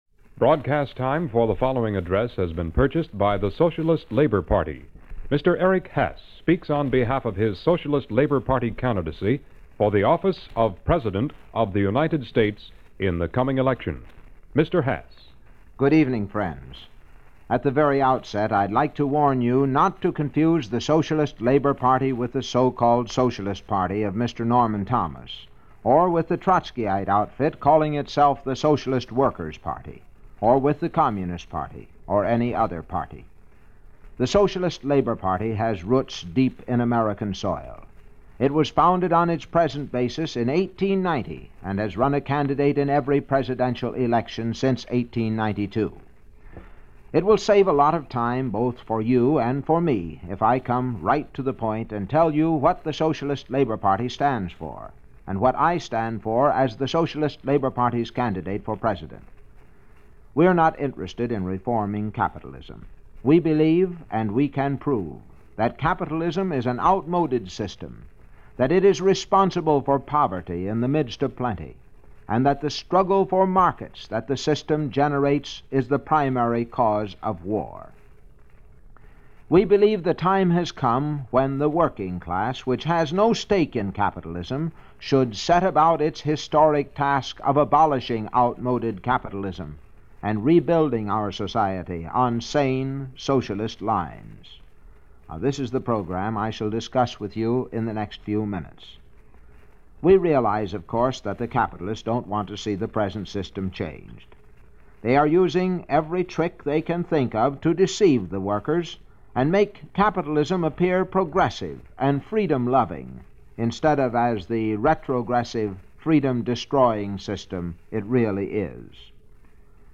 In this radio address by Eric Hass, who was the Socialist Labor Party candidate for President in 1956, he reminds the audience that The Socialist Labor Party shouldn’t be confused with The Socialist Party, or the Socialist Workers Party, or even The Communist Party.